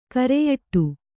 ♪ kareyaṭṭu